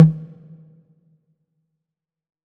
6CONGA OP.wav